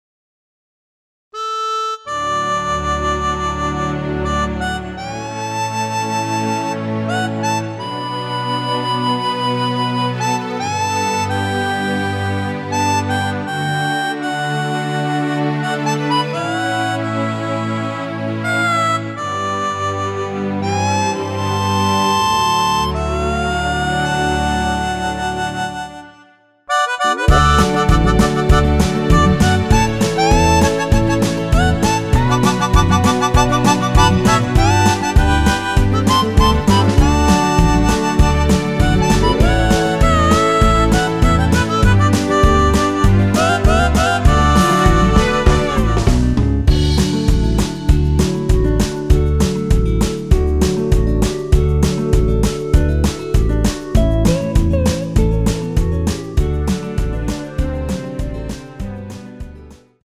원키에서(+2)올린 멜로디 포함된 MR입니다.(미리듣기 확인)
D
◈ 곡명 옆 (-1)은 반음 내림, (+1)은 반음 올림 입니다.
앞부분30초, 뒷부분30초씩 편집해서 올려 드리고 있습니다.
중간에 음이 끈어지고 다시 나오는 이유는